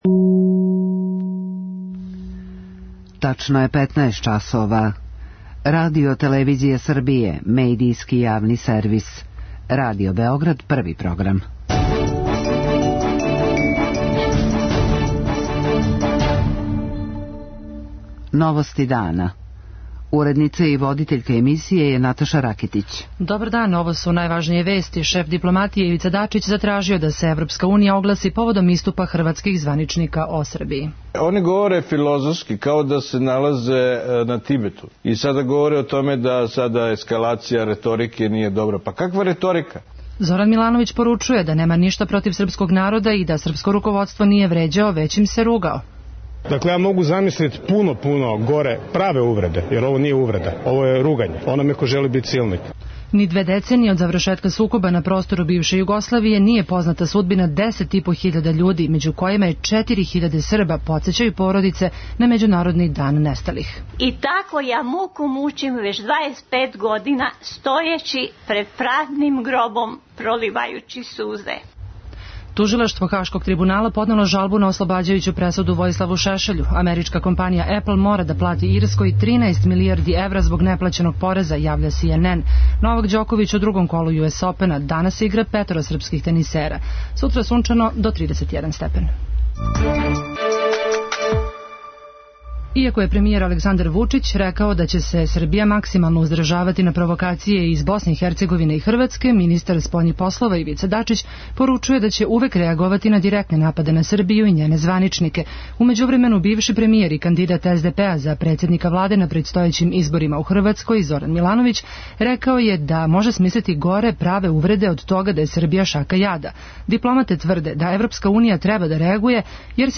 novosti3008.mp3